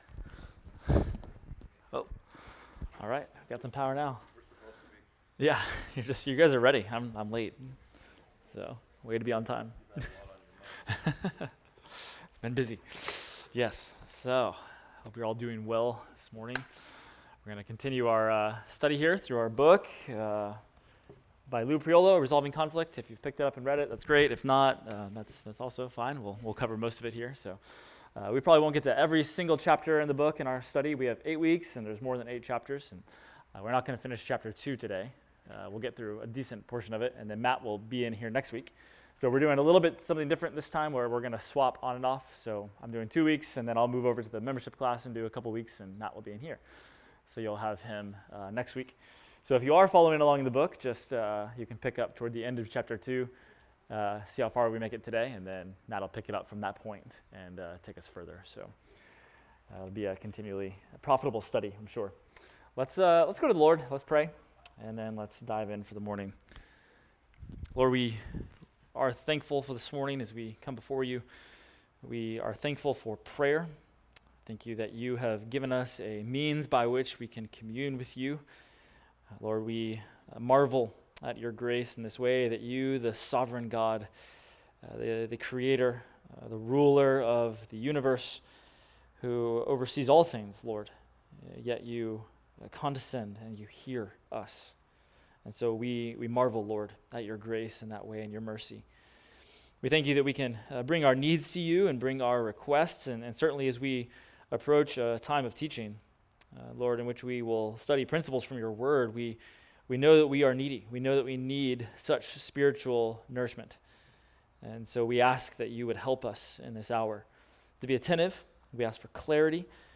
Adult Sunday School – Resolving Conflict – Week 6